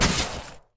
etfx_explosion_frost.wav